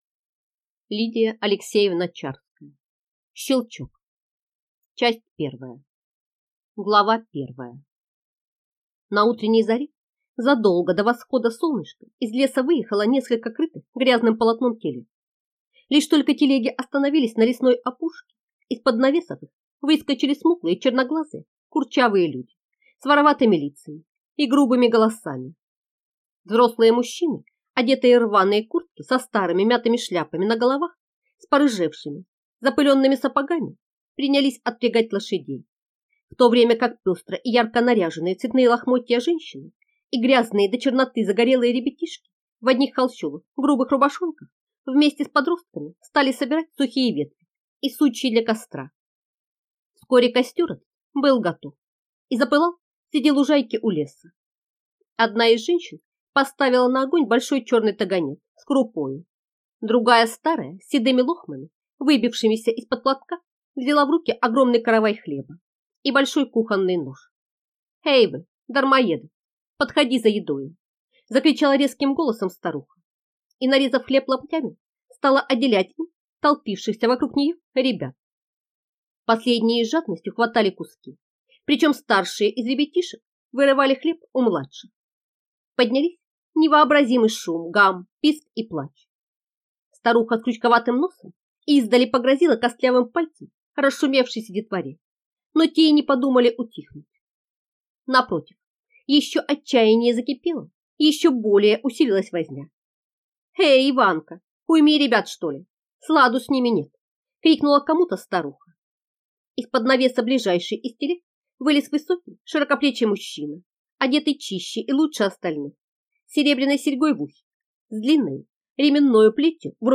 Аудиокнига Щелчок | Библиотека аудиокниг